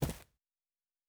Footstep Carpet Running 1_04.wav